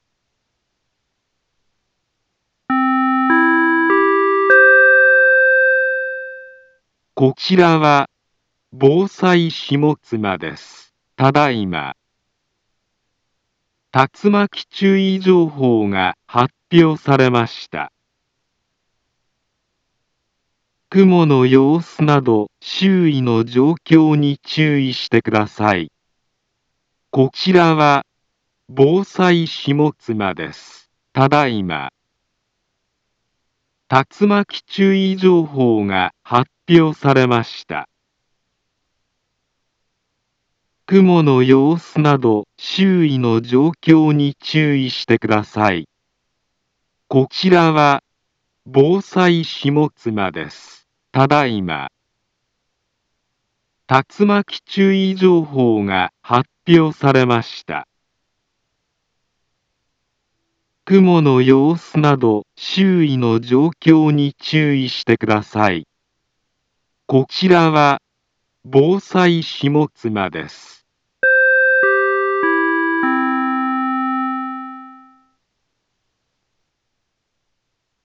Back Home Ｊアラート情報 音声放送 再生 災害情報 カテゴリ：J-ALERT 登録日時：2024-09-18 17:59:21 インフォメーション：茨城県北部、南部は、竜巻などの激しい突風が発生しやすい気象状況になっています。